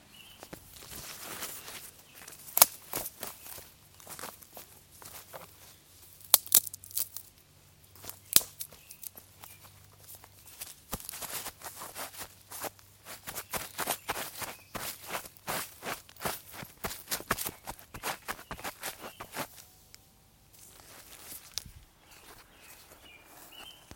描述：鸟儿歌唱
Tag: 鸟鸣声 气氛 户外 线索 自然 现场录音